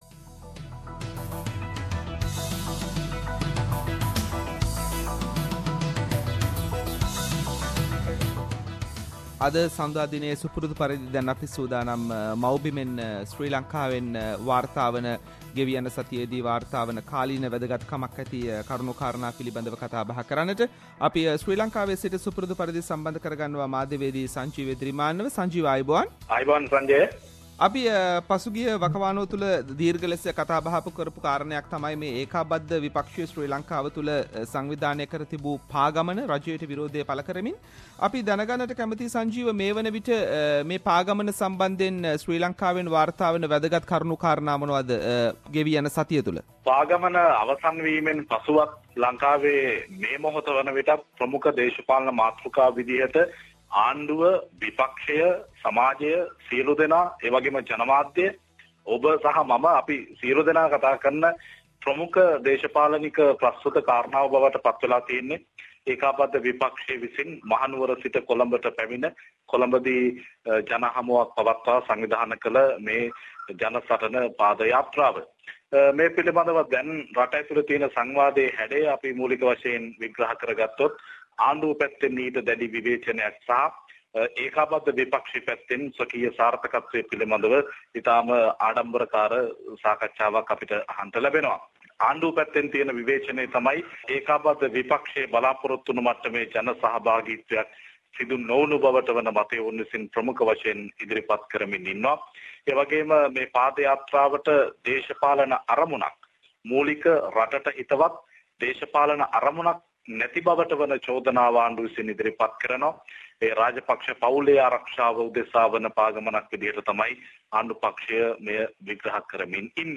Weekly Sri Lankan news wrap – What happened after joint opposition's ‘Padayatra”